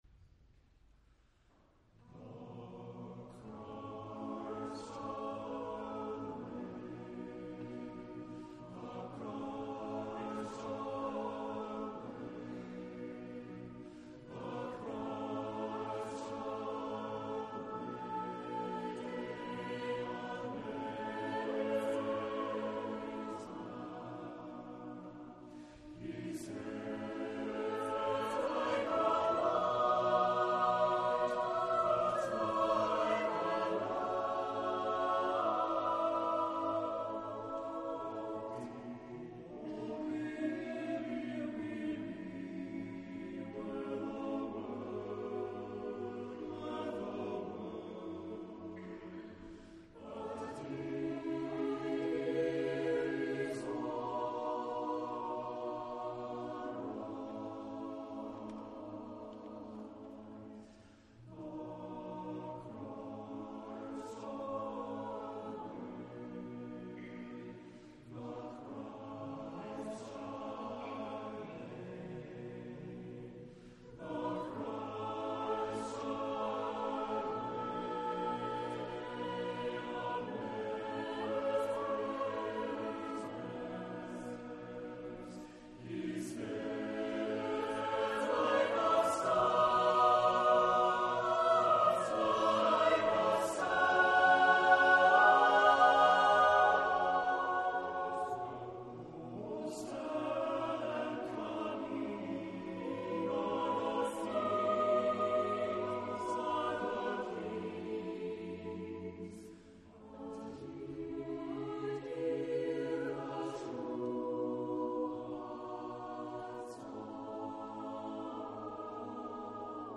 Voicing: SSAATB